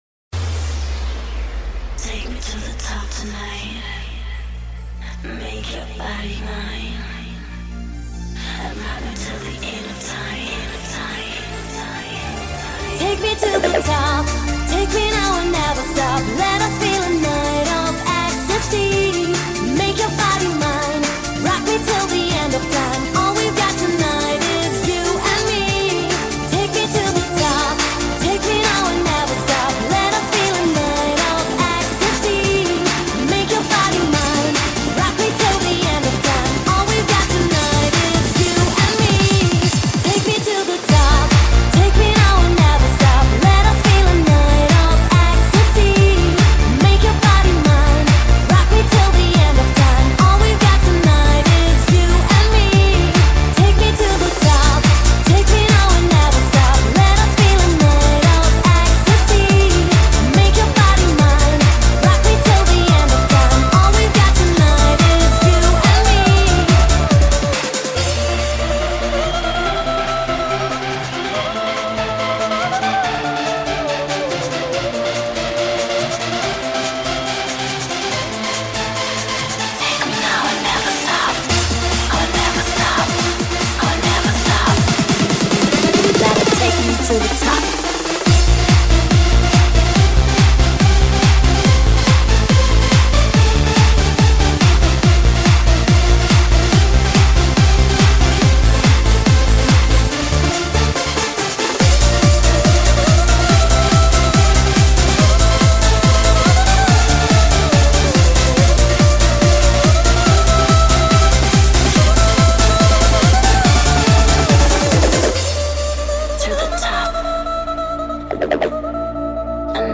世界R&BDJ舞曲 激情巅峰跨越全球 迪厅王者至尊
炽热的音乐疯狂的节奏充满激情的活力